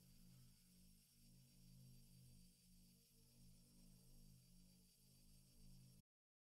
Laser Beam.mp3